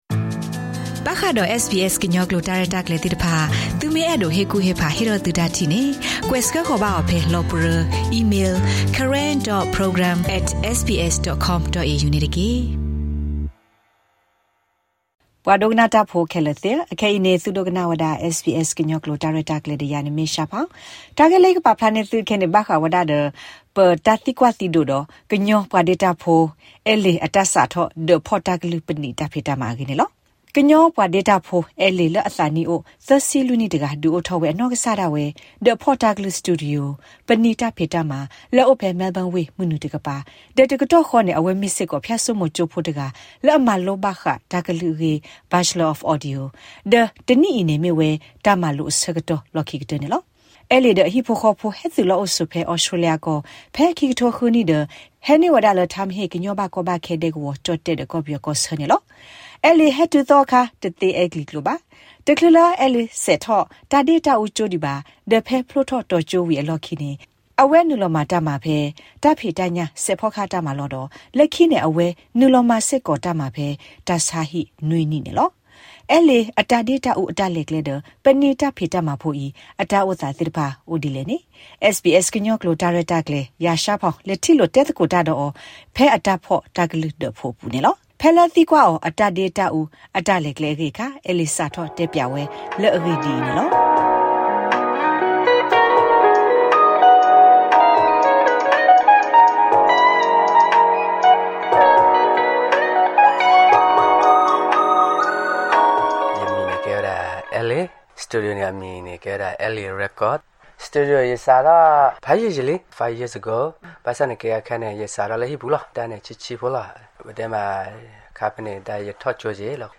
interview
in L A Record studio